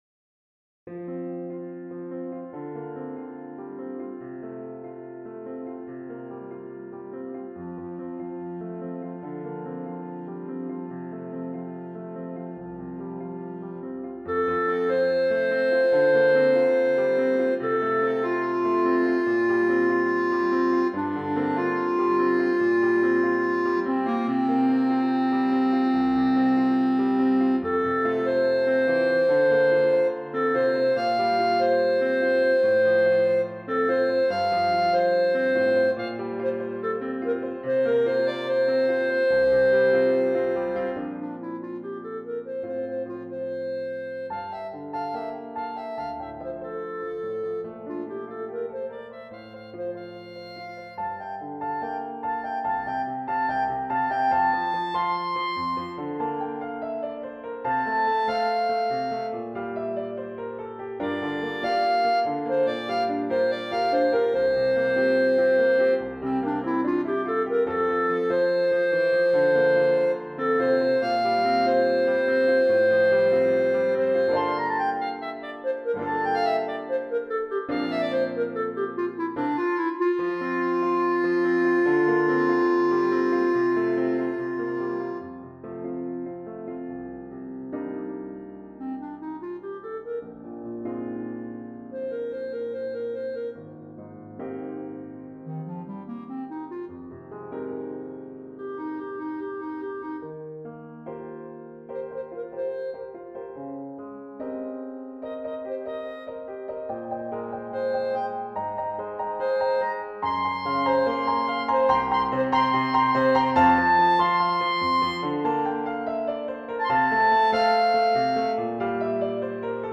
for Clarinet and Piano (2021)